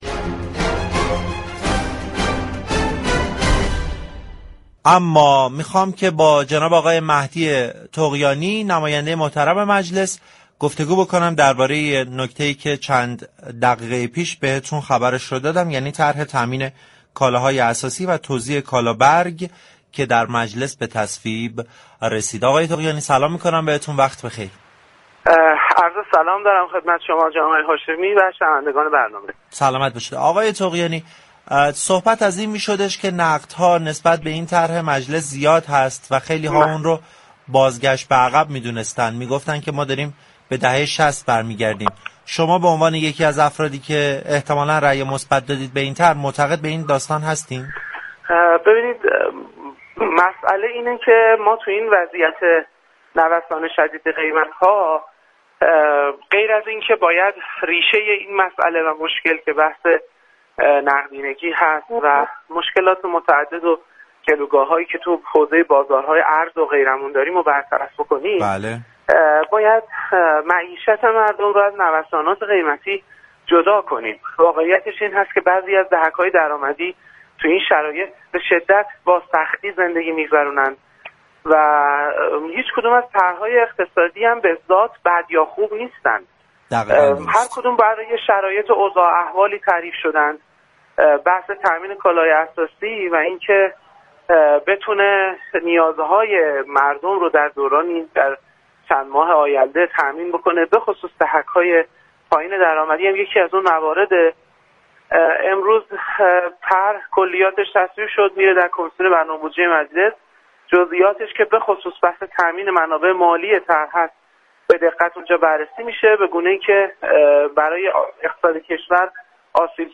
مهدی طغیانی در گفتگو با برنامه بازار تهران